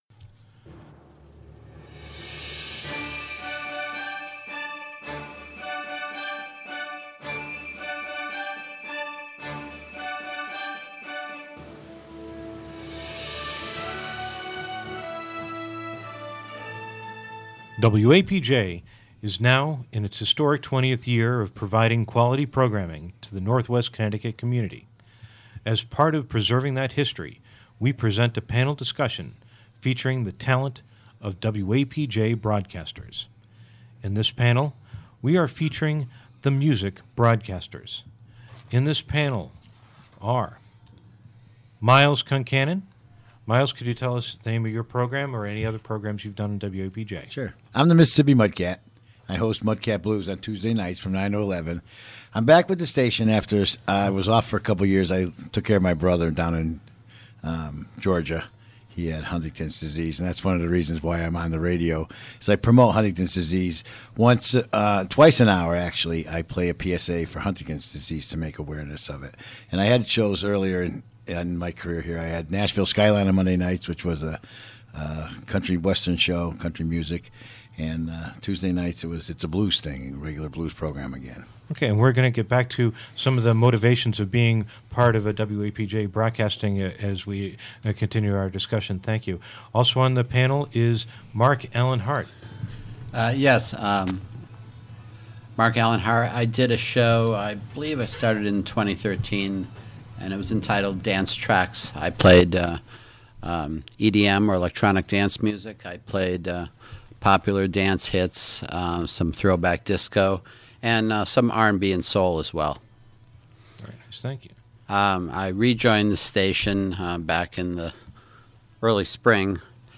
This special panel that features MUSIC broadcasters of the WAPJ station, this is the first of two such panels recorded in 2017